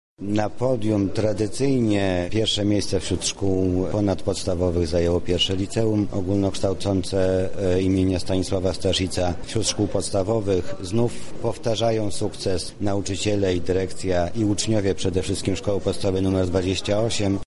Duża liczba wyróżnionych bardzo satysfakcjonuje władze miasta. Mamy w tym roku ponad 460 laureantów – mówi wiceprezydent Mariusz Banach.